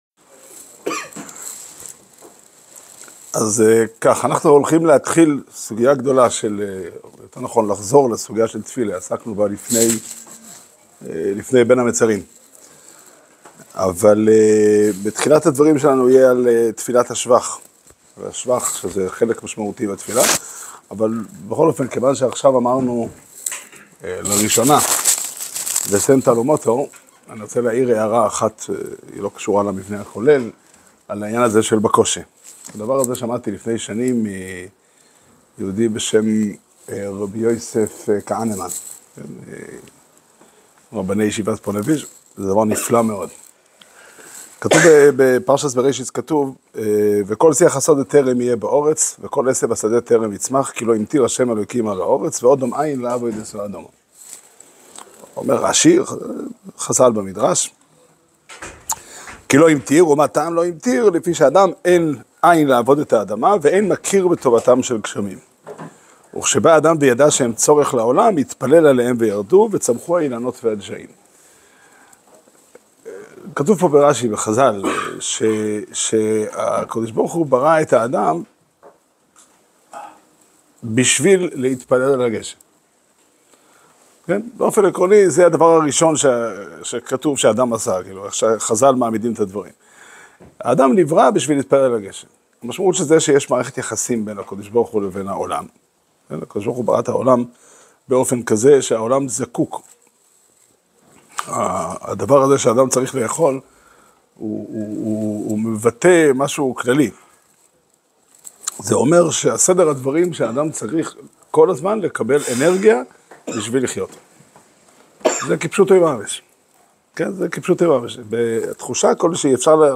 שיעור שנמסר בבית המדרש פתחי עולם בתאריך ו' חשוון תשפ"ה